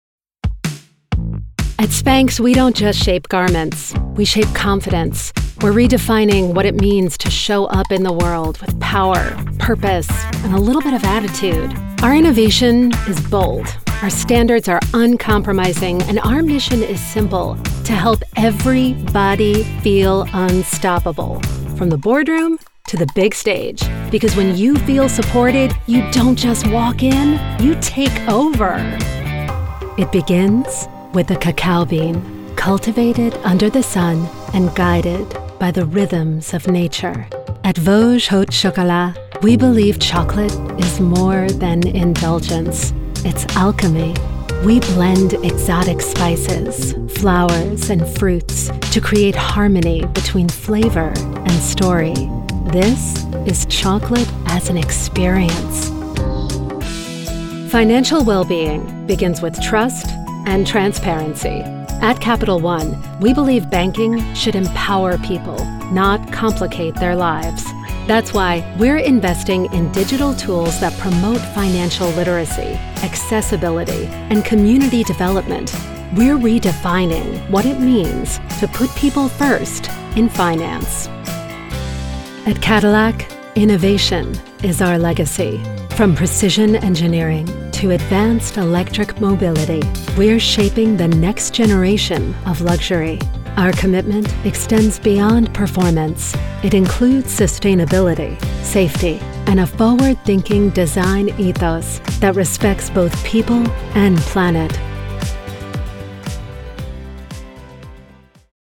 Corporate & Industrial Voice Overs
Adult (30-50) | Older Sound (50+)